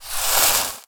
fireball_conjure_03.wav